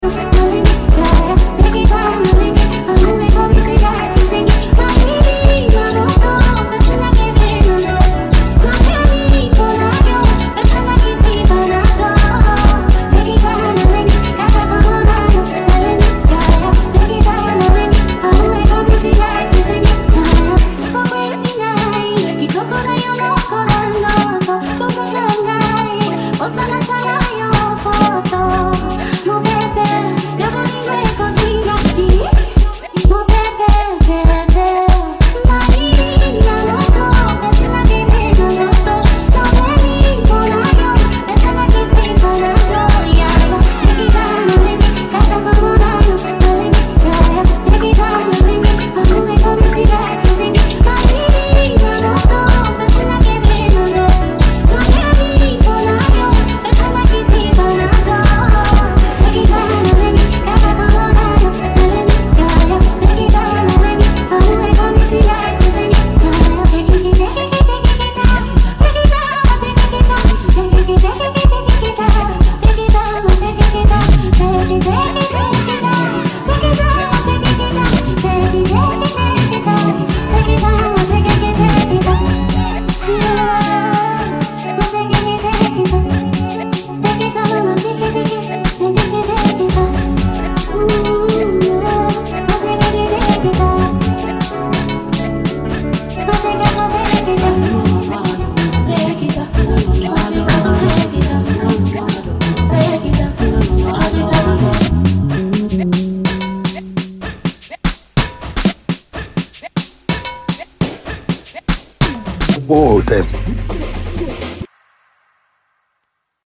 Award winning singer-songsmith